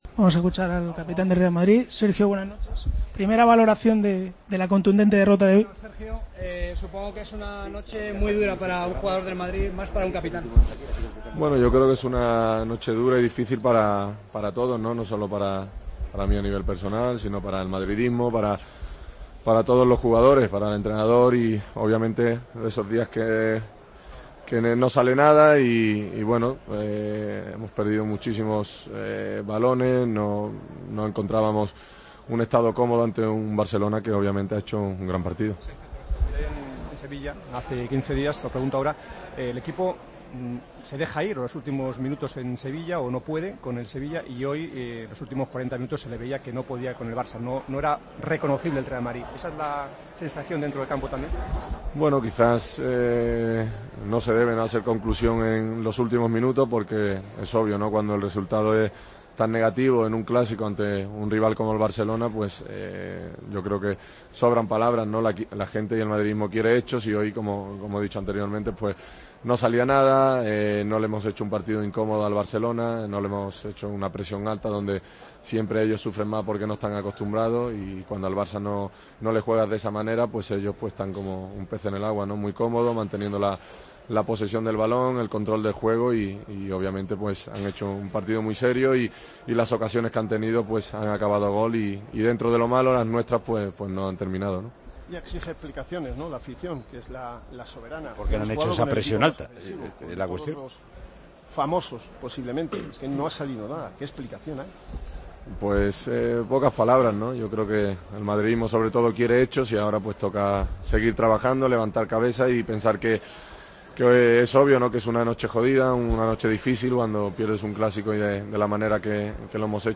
El jugador del Real Madrid atendió a los medios de comunicación después del partido:"Es una noche dura y difícil para todos, para el madridismo, para los jugadores, entrenador. Es de esos días que no sale nada. Perdimos muchos balones. No hemos estado comodos antes el Barcelona. No se debe hacer conclusión en los últimos minutos con un resultado tan claro. El madridismo quiere hechos. Han hecho un partido muy serio y sus ocasiones han acabado dentro. Es una noche jodida, después de perder el Clásico de la manera que lo hemos hecho. Seguimos vivos en las 3 competiciones y hay que levantar la cabeza. Creemos en el entrenador y todos tenemos que estar unidos".